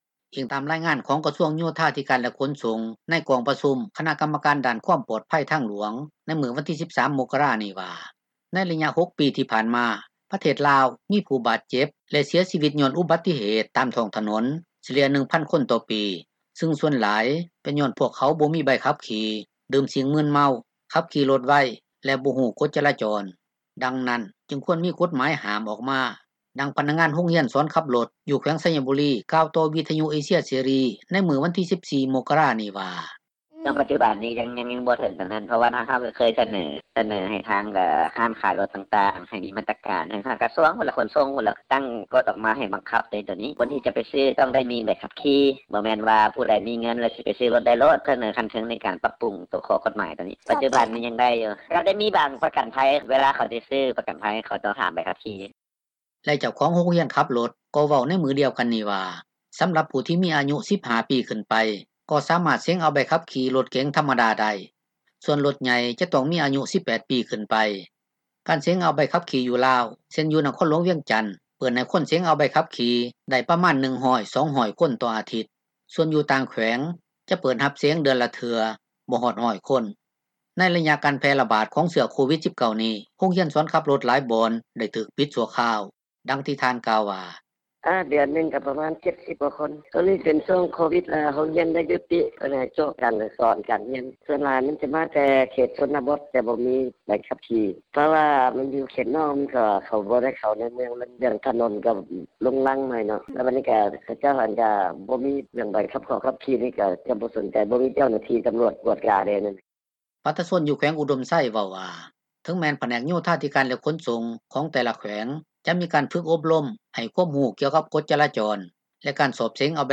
ດັ່ງພະນັກງານໂຮງຮຽນສອນຂັບຣົຖ ຢູ່ແຂວງໄຊຍະບູຣີ ເວົ້າຕໍ່ວິທຍຸເອເຊັຍເສຣີ ໃນມື້ວັນທີ 14 ມົກກະຣານີ້ວ່າ:
ດັ່ງເຈົ້າໜ້າທີ່ທີ່ເຮັດວຽກ ກ່ຽວກັບການສຶກສາຊາວໜຸ່ມລາວ ກ່າວວ່າ: